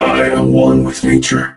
robo_bo_die_02.ogg